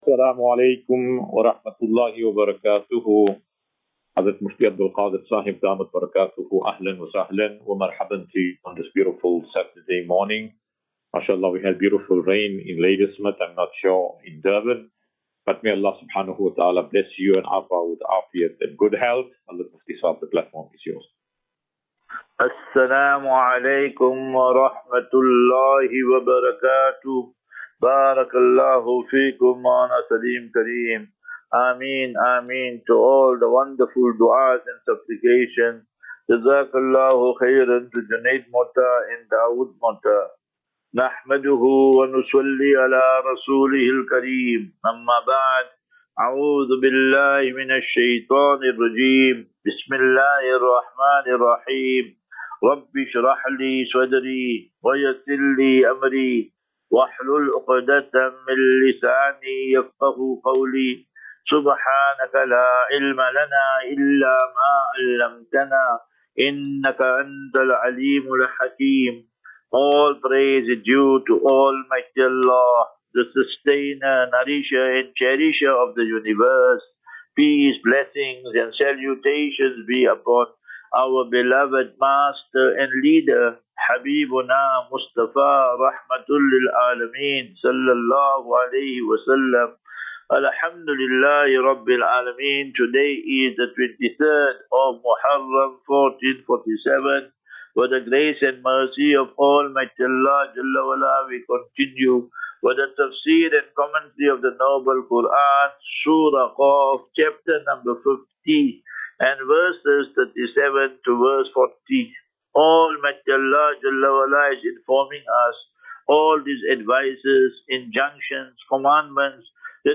Assafinatu - Illal - Jannah. QnA.